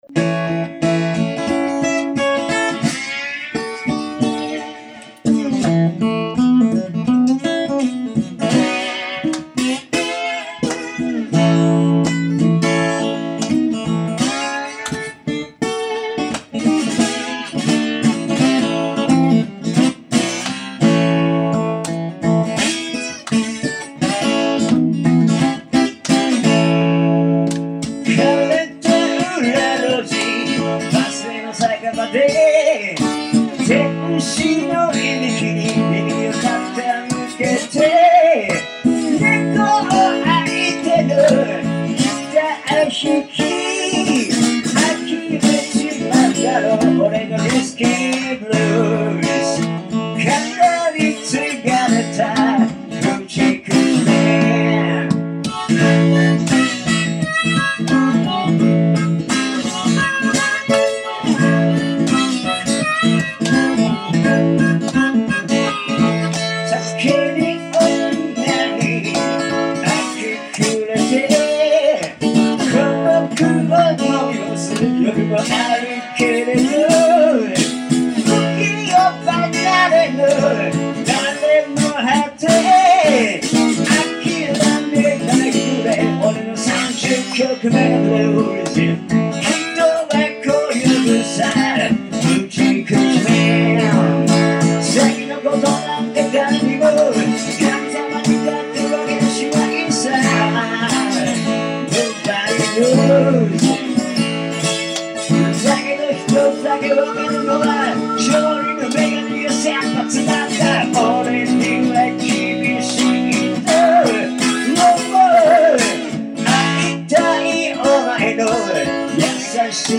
ToILeT special Xmas Live.